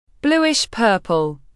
Bluish-purple /ˈbluː.ɪʃ pɜː.pəl/